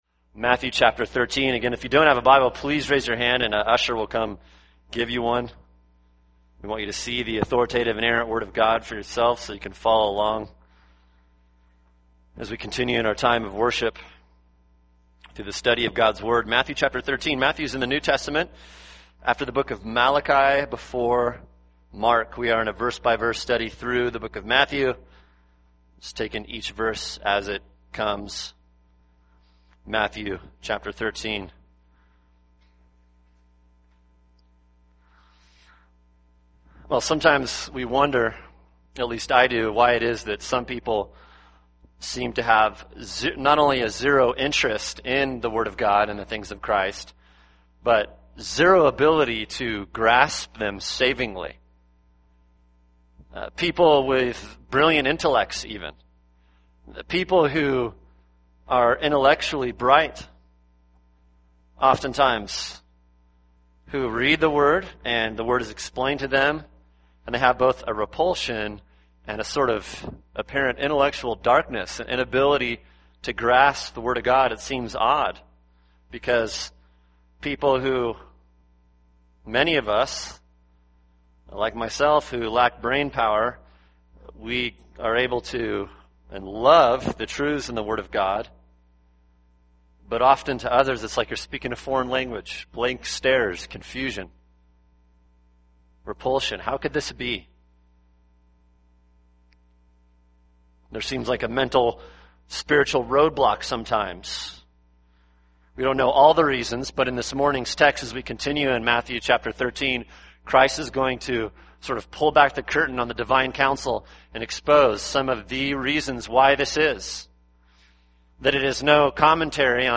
[sermon] Matthew 13:10-17 – God’s Sovereign Grace | Cornerstone Church - Jackson Hole